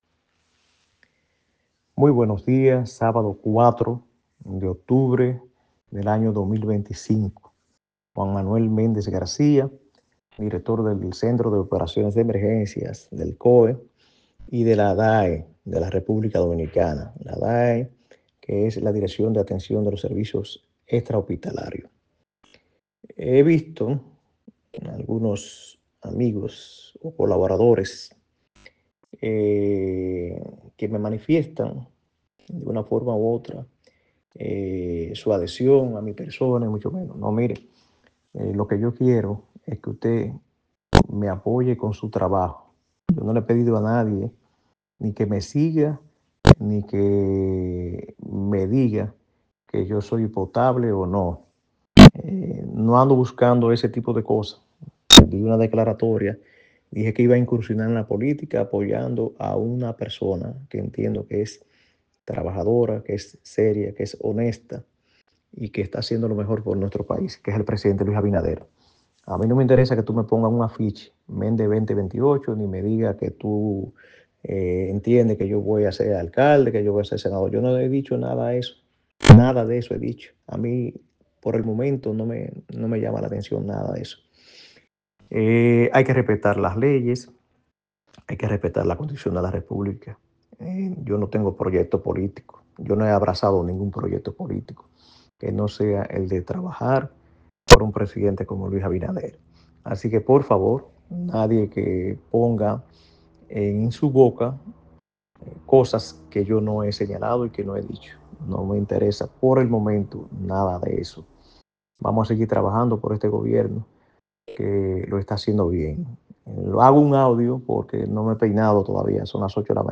En un breve audio que remitió a los medios de comunicación, el exmilitar y director del Centro de Operaciones de Emergencia (COE) precisó que no está buscando una candidatura a diputado, ni a senador o alcalde.